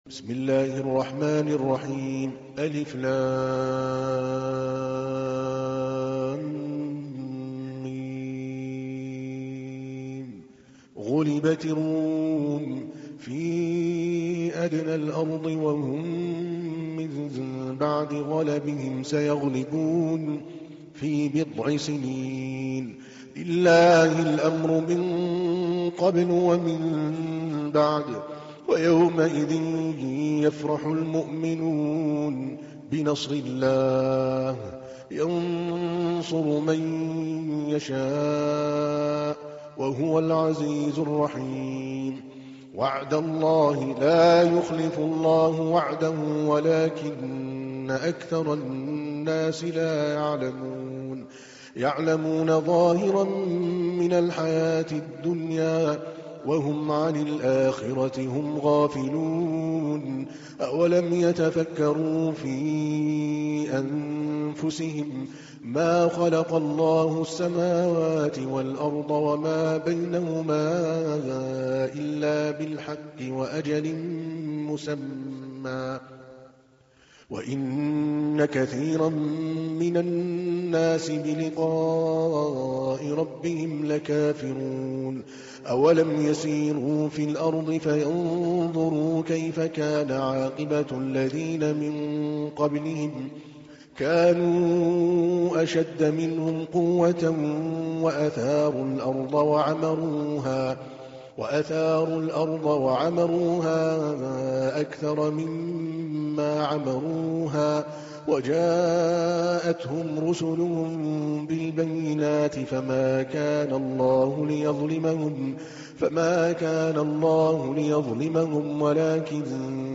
تحميل : 30. سورة الروم / القارئ عادل الكلباني / القرآن الكريم / موقع يا حسين